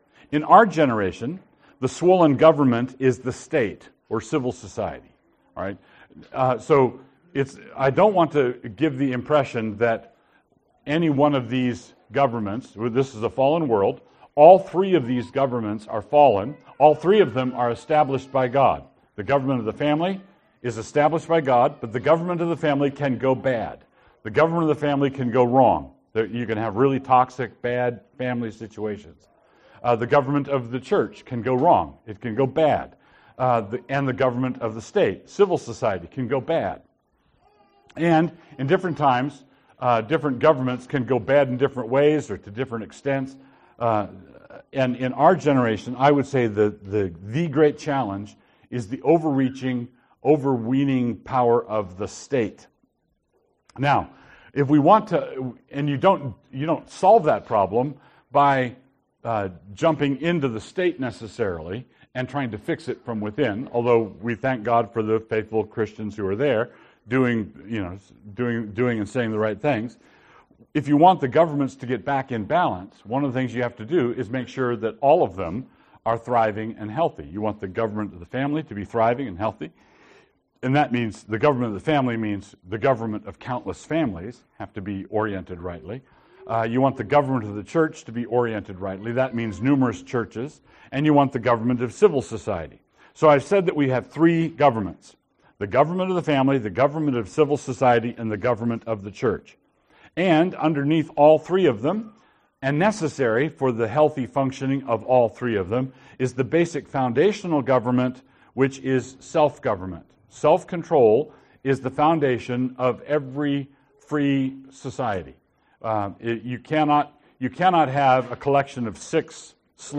FamilyCampPart2-GovernmentsUnderHeaven-withQandA.mp3